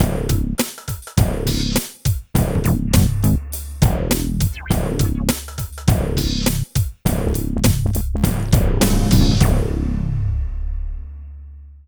84 LOOP   -L.wav